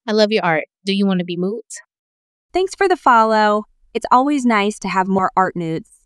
ムーツ